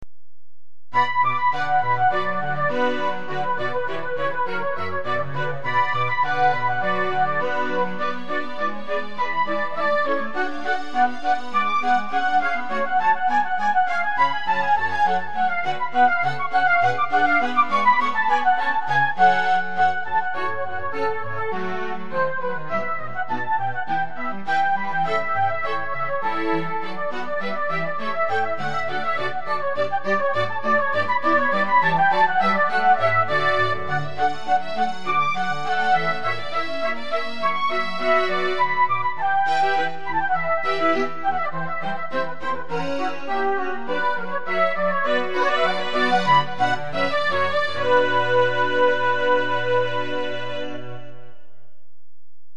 テンポがわずかに遅かった。ビブラートがかかりすぎ。